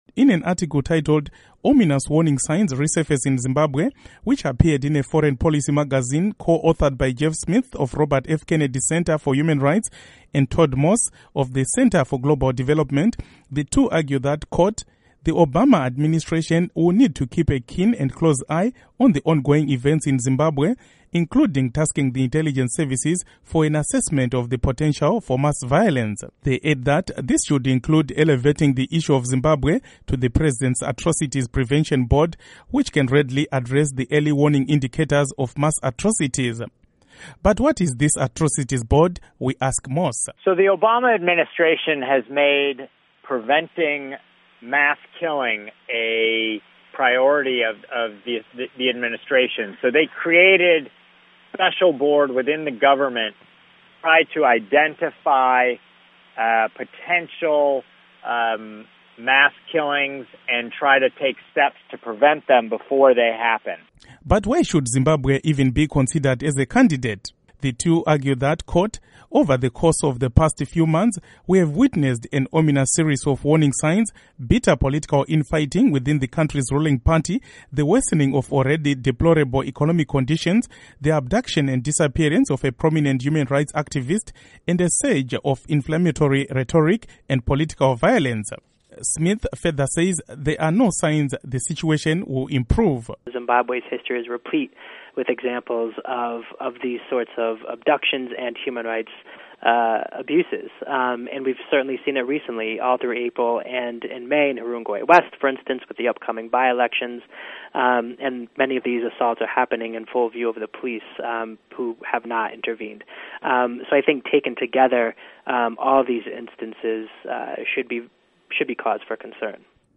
Report on Zimbabwe Crisis